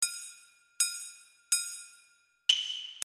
Sono in tutto 3 tracce e sono state realizzate usando sample di percussioni “kabuki” (eredità della cultura teatrale e musicale giapponese), i cui suoni mi sono sembrati molto suggestivi.
La prima traccia ha un suono realizzato con una specie di triangolo o campana sorda e si accompagna, in maniera sincopata, alla melodia del canto fin dall' inizio creando una sorta di continuita' ritmica.
Kabuki1.mp3